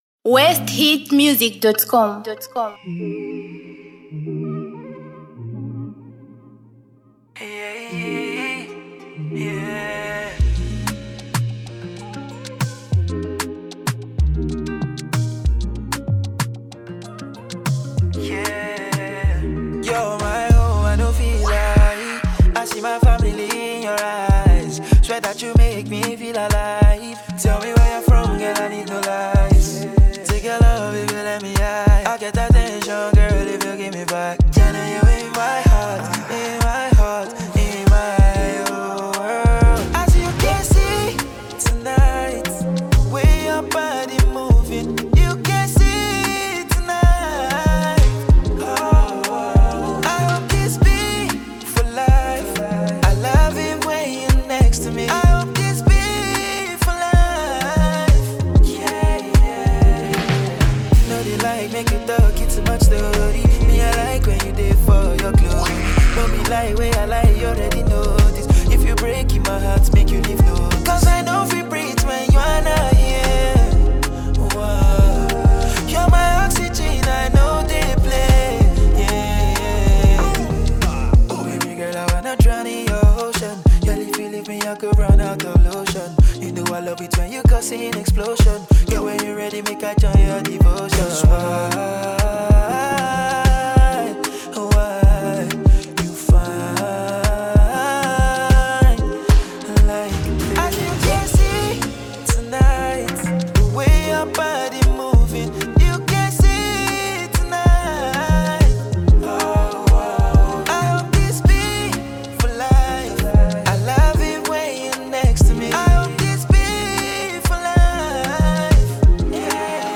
With infectious beats and memorable lyrics